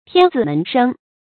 發音讀音
成語簡拼 tzms 成語注音 ㄊㄧㄢ ㄗㄧˇ ㄇㄣˊ ㄕㄥ 成語拼音 tiān zǐ mén shēng 發音讀音 常用程度 一般成語 感情色彩 中性成語 成語用法 作主語、賓語、定語；用于書面語 成語結構 偏正式成語 產生年代 古代成語 成語謎語 狀元